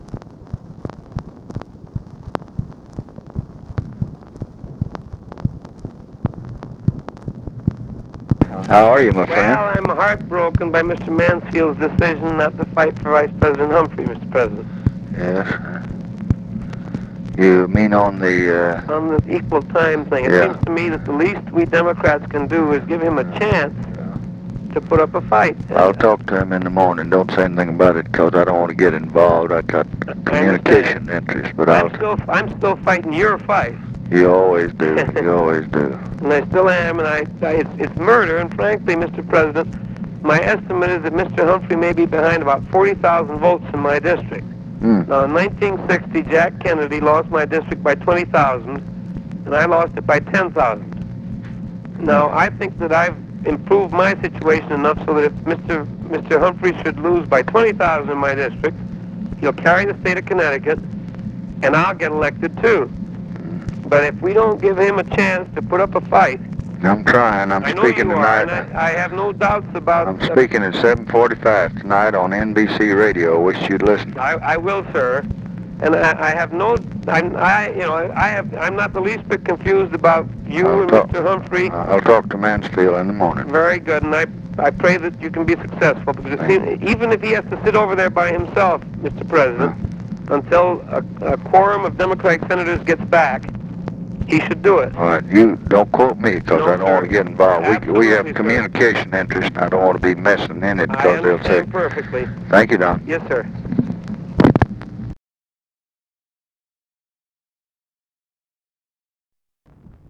Conversation with DONALD IRWIN, October 10, 1968
Secret White House Tapes